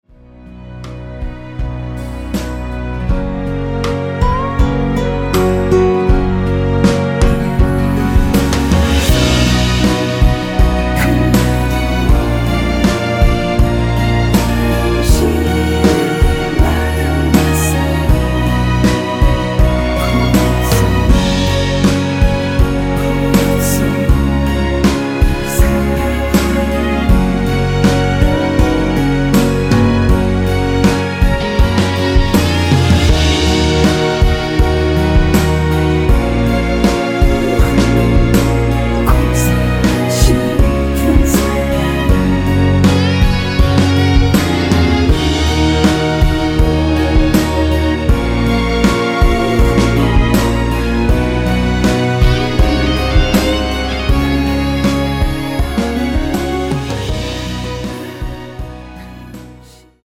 원키 코러스 포함된 MR입니다.
F#
앞부분30초, 뒷부분30초씩 편집해서 올려 드리고 있습니다.
중간에 음이 끈어지고 다시 나오는 이유는